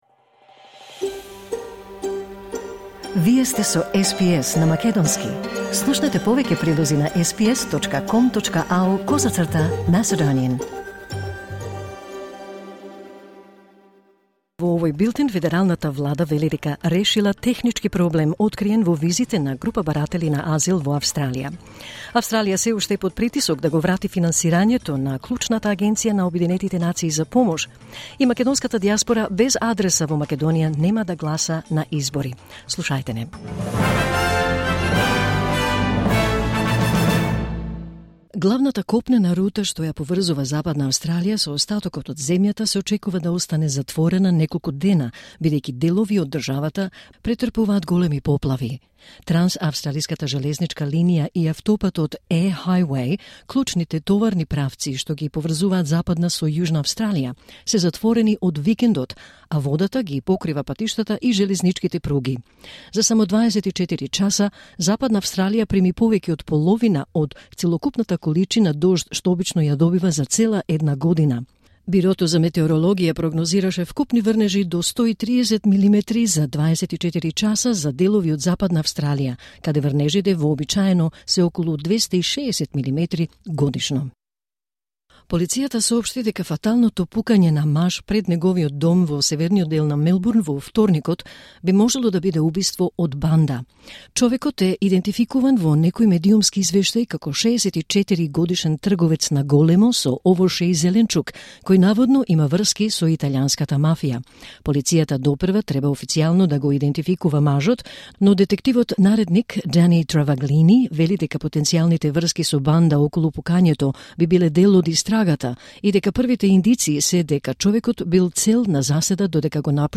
SBS Macedonian News 13 March 2024